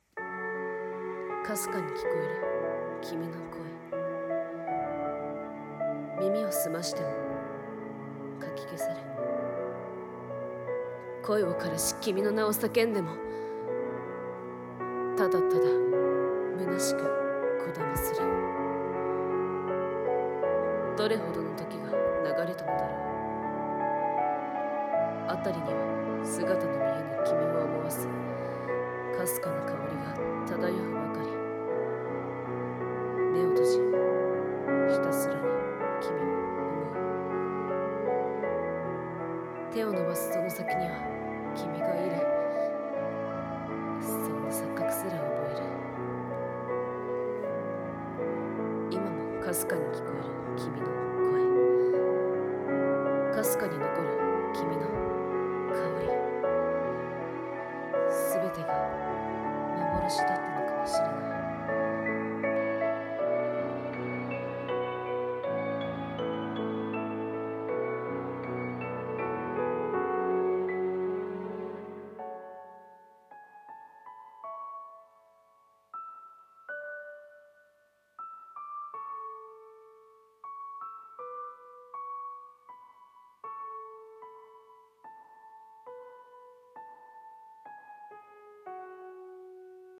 【 声劇 】「 微かなる幻影 」